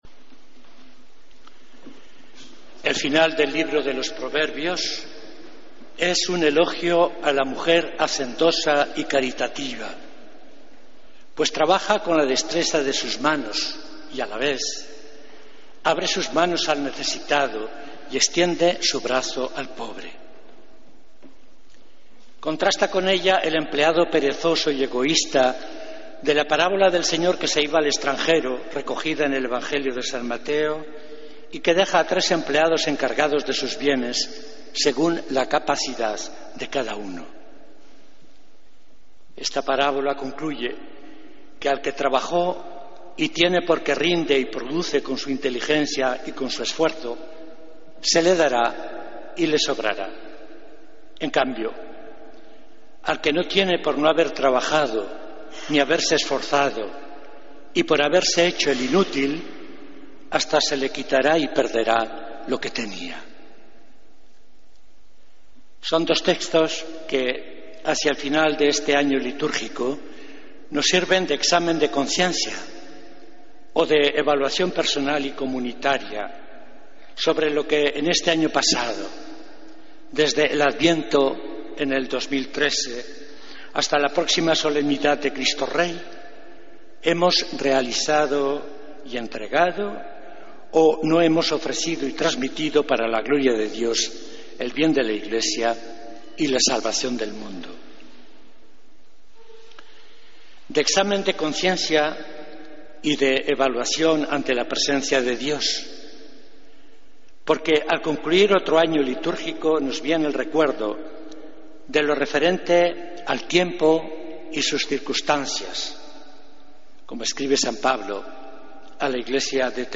Homilía del Domingo 16 de Noviembre de 2014